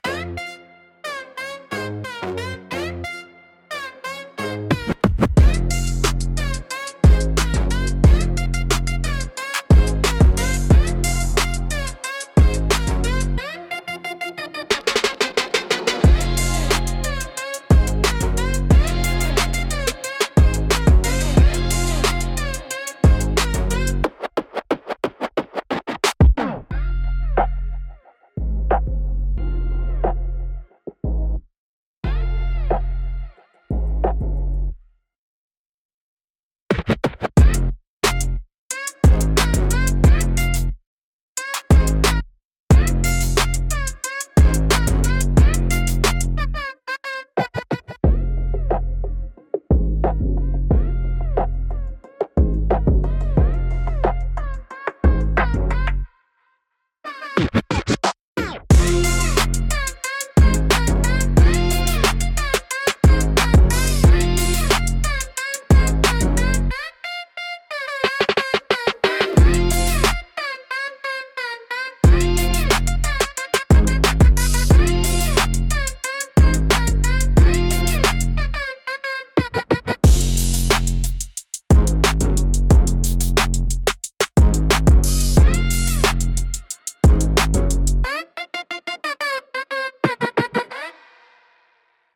Instrumental - Trap Gospel